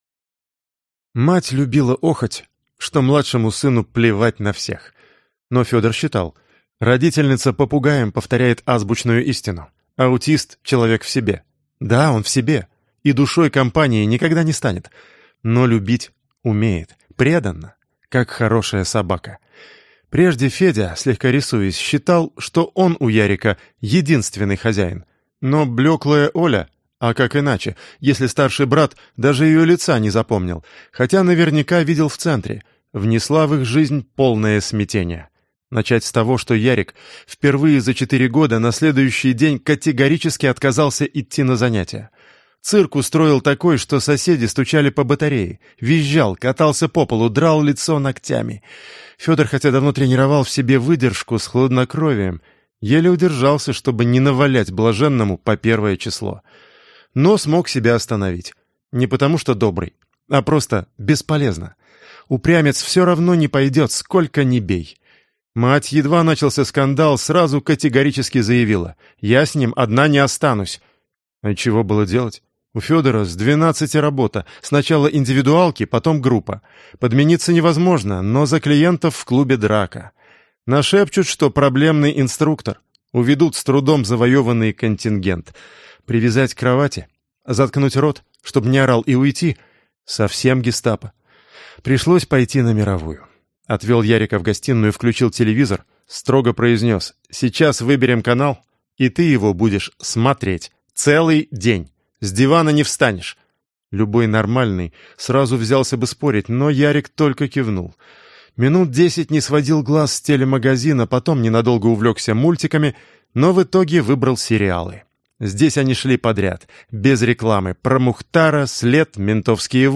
Аудиокнига Брат ответит | Библиотека аудиокниг
Прослушать и бесплатно скачать фрагмент аудиокниги